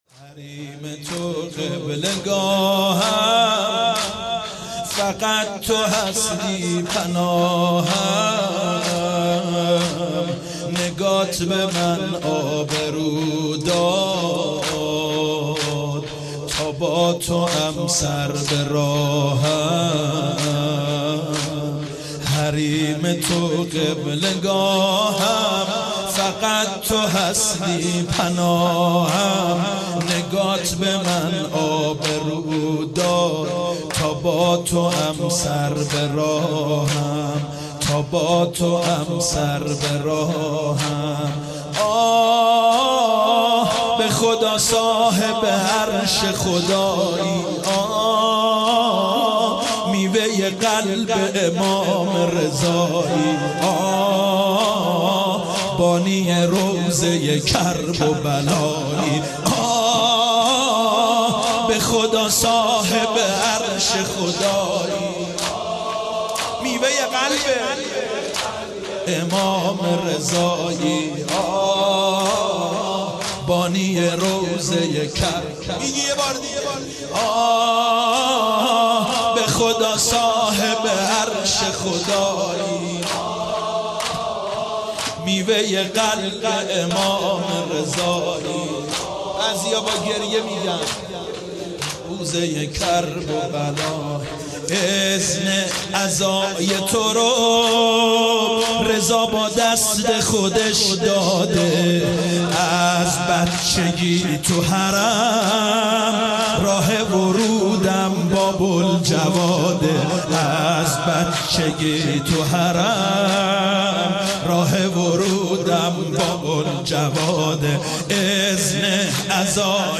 به مناسبت شب شهادت جوادالائمه(ع)